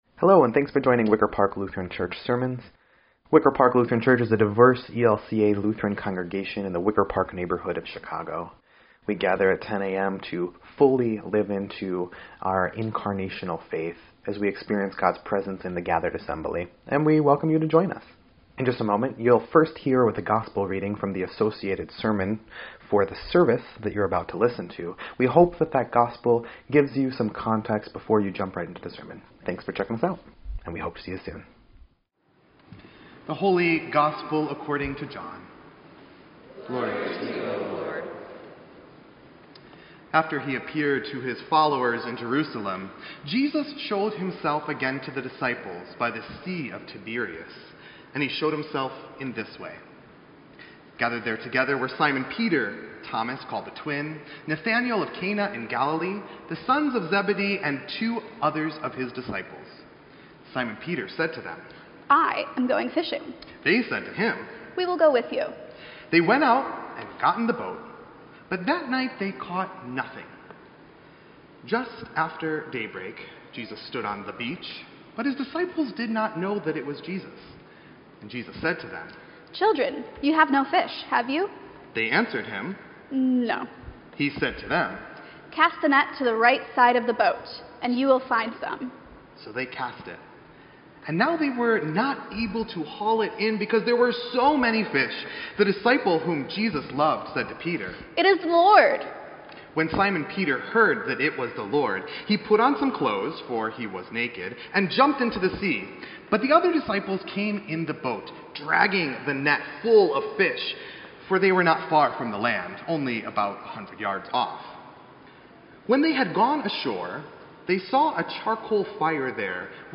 Third Sunday in Easter